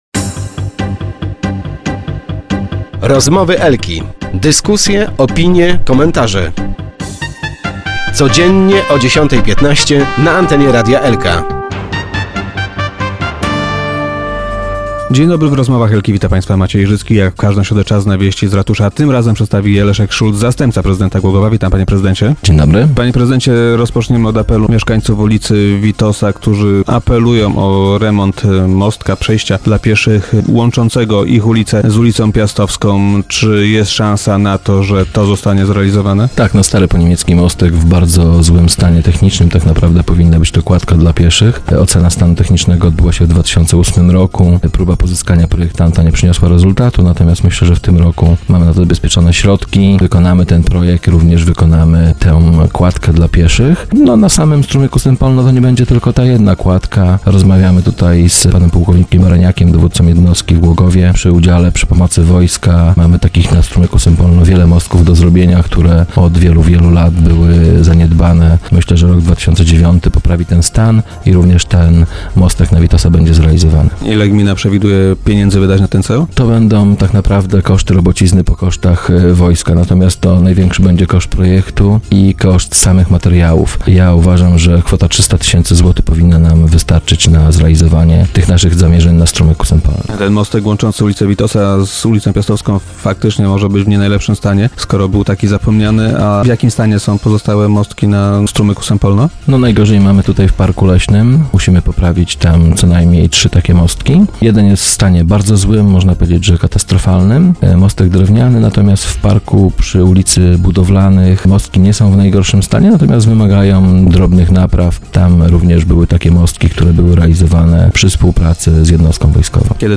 thumb_wizualizacja.jpgGłogów. Później niż planowano rozpocznie się remont budynku typu Lipsk przy ulicy Merkurego. Powodem opóźnienia są nie tylko lokatorzy, którzy jeszcze w nim mieszkają, ale także mrozy. - Wszystko wskazuje na to, że rozpoczęcie prac przesuniemy o miesiąc - powiedział wiceprezydent Leszek Szulc, gość dzisiejszych Rozmów Elki.